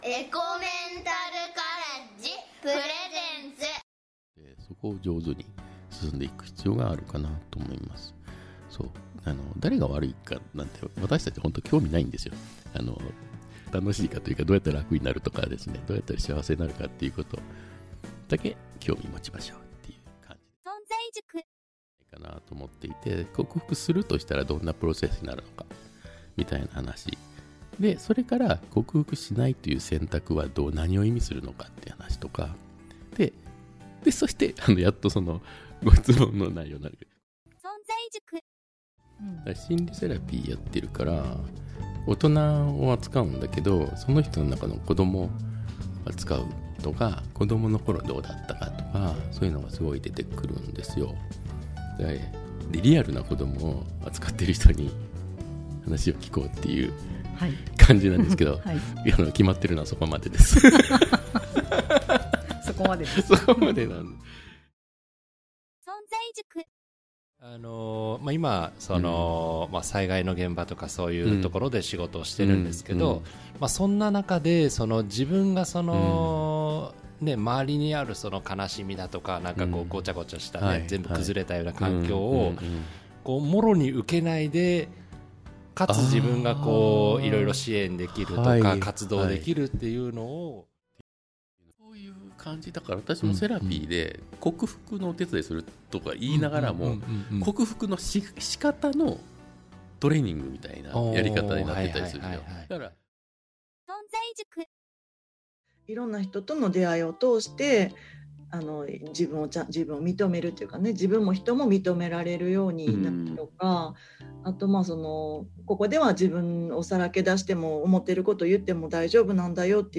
音声 – 1/fゆらぎ
※ラジオ音声はゆめのたね放送局からの許可を得て公開しています。